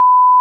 bleep.wav